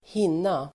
Uttal: [²h'in:a]